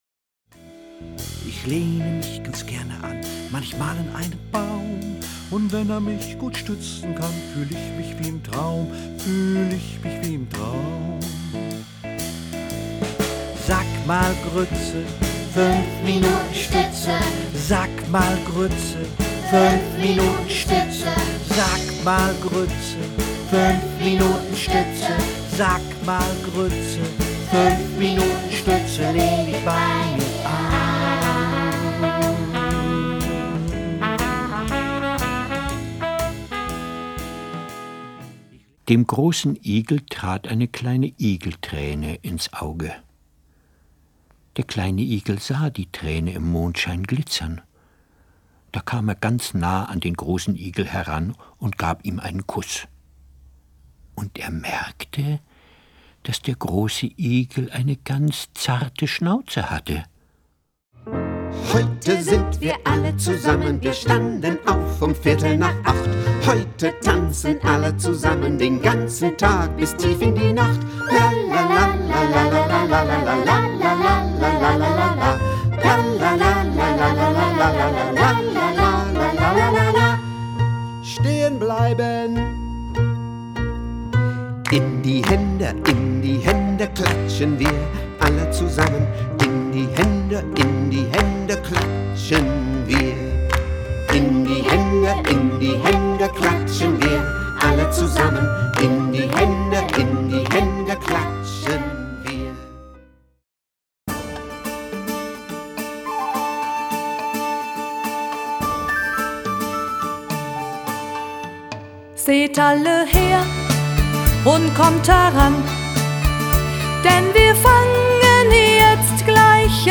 Lieder, Verse und Geschichten
Spiel- und Bewegungslieder